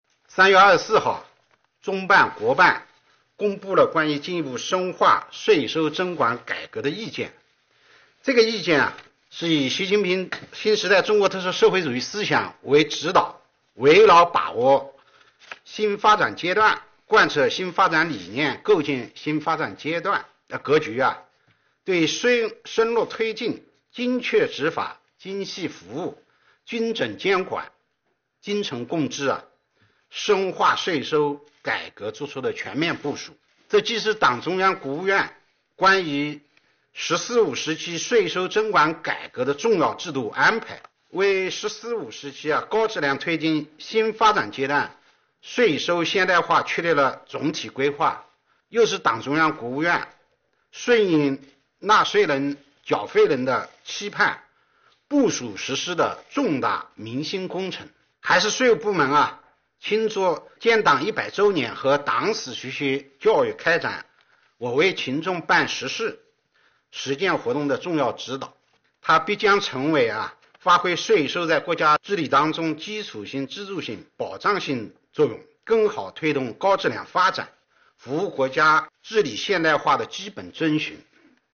3月29日，国务院新闻办公室举行新闻发布会，国家税务总局相关负责人介绍《关于进一步深化税收征管改革的意见》（以下简称《意见》）有关情况。
国家税务总局党委委员、副局长任荣发为您解读↓